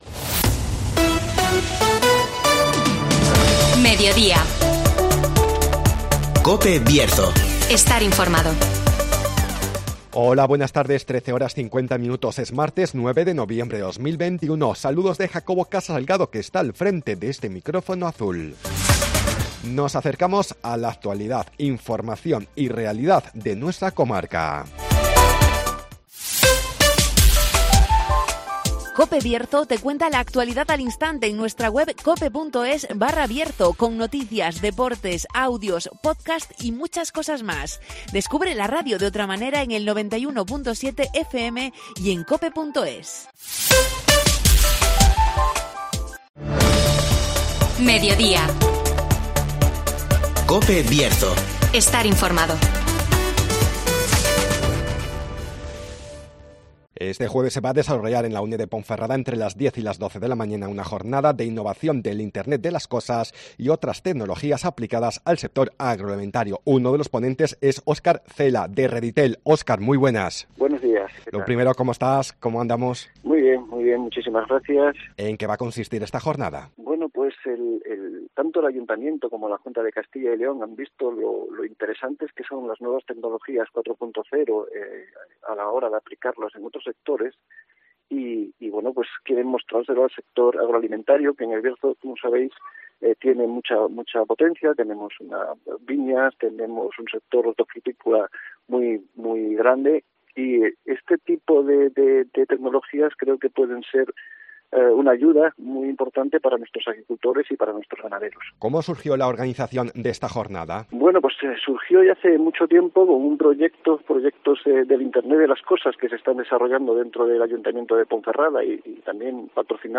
Jornada de innovación del Internet de las Cosas y otras tecnologías aplicadas al sector agroalimentario (Entrevista